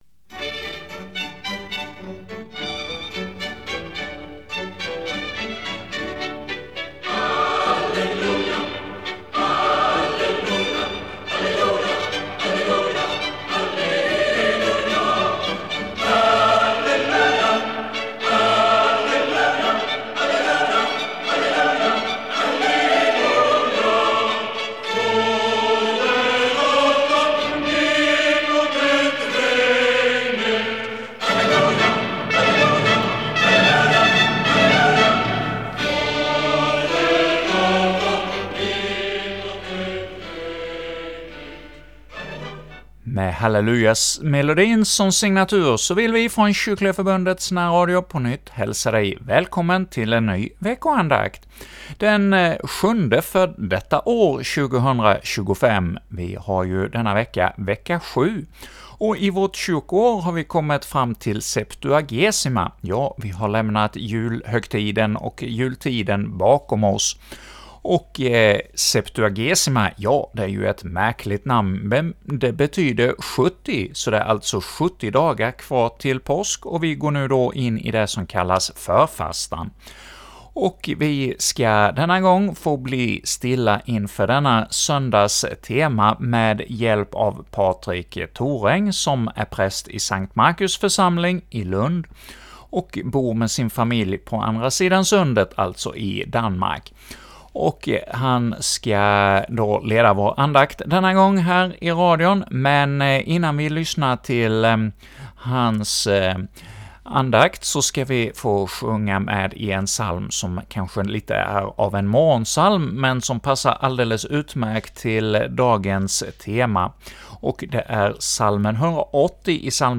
leder andakt inför Septuagesima